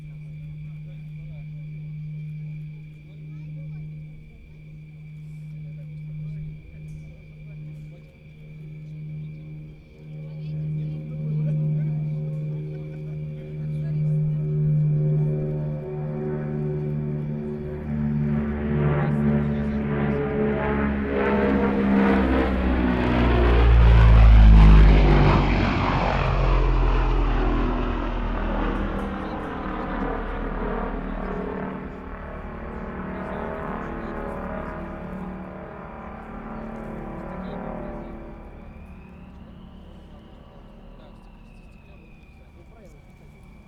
100 лет ВВС. Пролет Ан-22, аэродром ЛИИ.
Запись произведена на DAT-магнитофон TASCAM DA-P1 c микрофонов AKG C577 с круговой направленностью . Расстояние между микрофонами 1,3метра. Специально не подвергалась никакой обработке. Если на Вашей системе регуляторы тембров находятся в крайних правых положениях, то при нормальной громкости прослушивания возможно повреждение динамиков.
an-22_prolet.wav